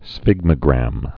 (sfĭgmə-grăm)